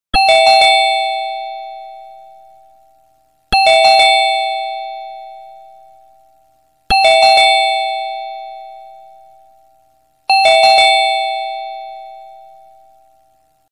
Звуки звонка в дверь
Современный дверной звонок